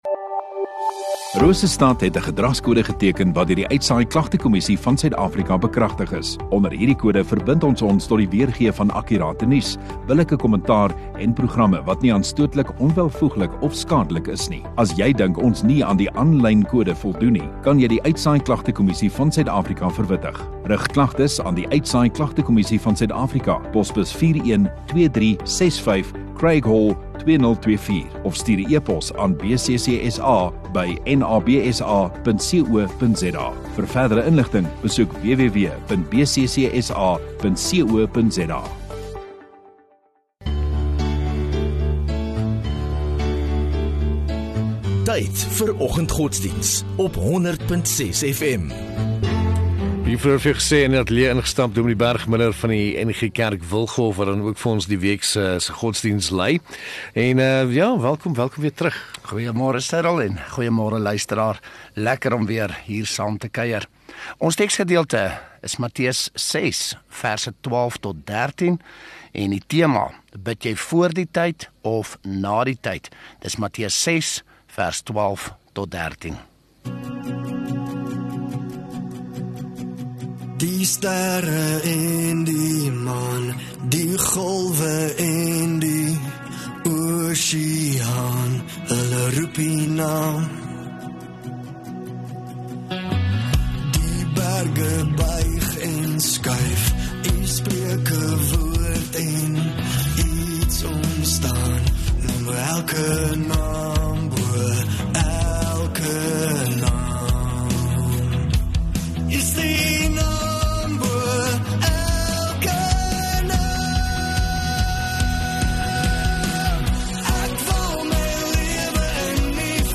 20 Sep Woensdag Oggenddiens